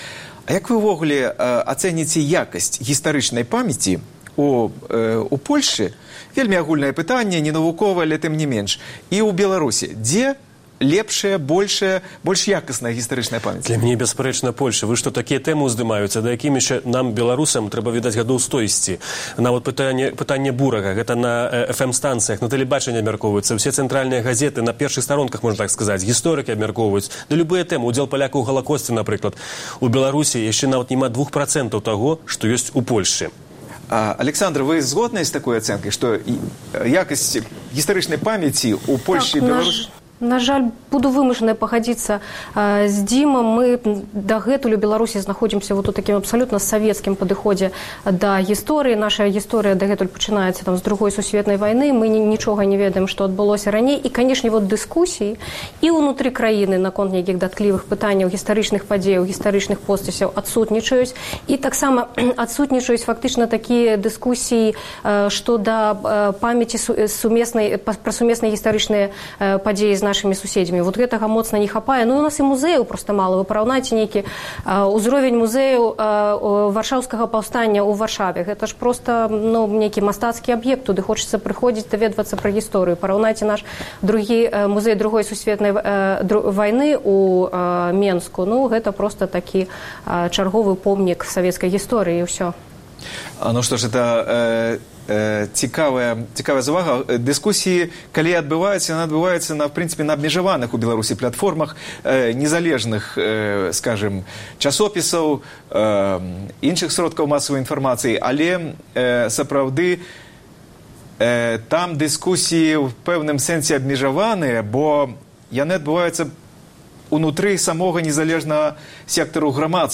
А зараз – скарочаная радыёвэрсія перадачы.